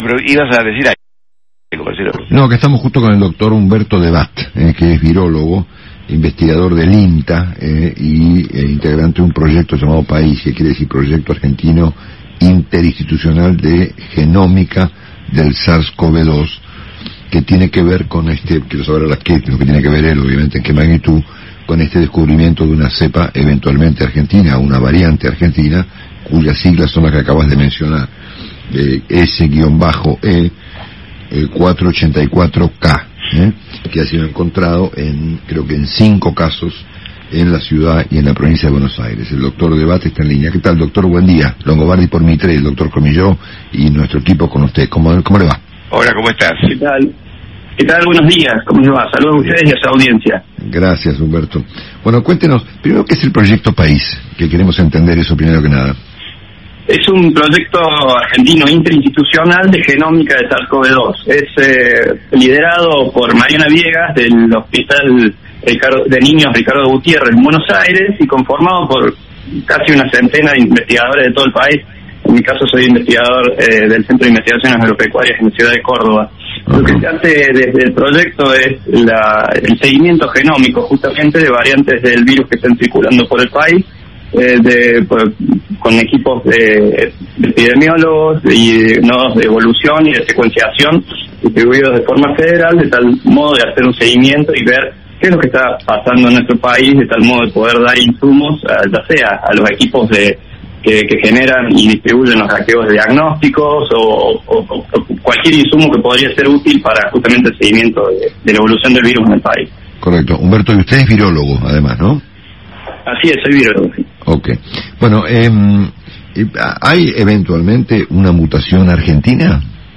en diálogo con Radio Mitre
entrevistado por el periodista Marcelo Longobardi en Cada Mañana por Radio Mitre